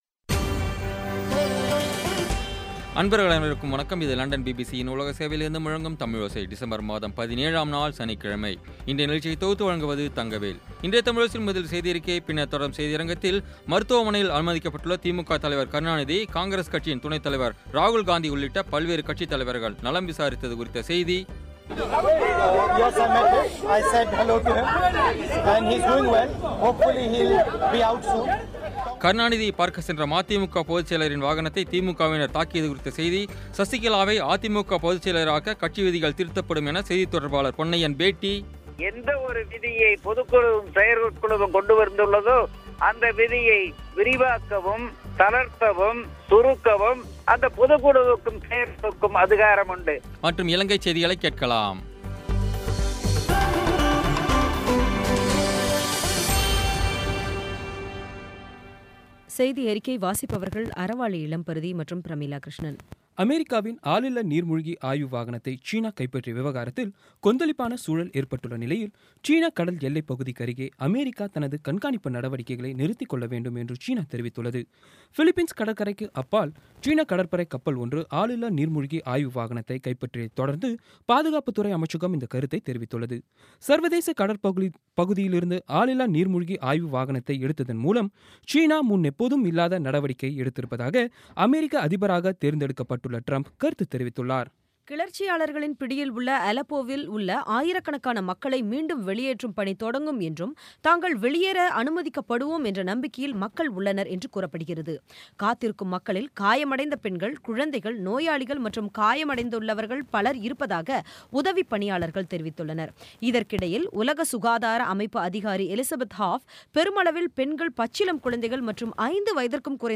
இன்றைய தமிழோசையில், முதலில் செய்தியறிக்கை, பின்னர் தொடரும் செய்தியரங்கத்தில், மருத்துவமனையில் அனுமதிக்கப்பட்டுள்ள திமுக தலைவர் கருணாநிதியை, காங்கிரஸ் கட்சியின் துணைத் தலைவர் ராகுல் காந்தி உள்ளிட்ட பல்வேறு கட்சித் தலைவர்கள் நலம் விசாரித்தது குறித்த செய்தி கருணாநிதியைப் பார்க்கச் சென்ற மதிமுக பொதுச் செயலரின் வாகனத்தை திமுகவினர் தாக்கியது குறித்த செய்தி சசிகலாவை அதிமுக பொதுச் செயலாளராக்க கட்சி விதிகள் திருத்தப்படும் என செய்தித் தொடர்பாளர் பொன்னையன் பேட்டி மற்றும் இலங்கைச் செய்திகளைக் கேட்கலாம்.